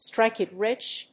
アクセントstríke it rích 《口語》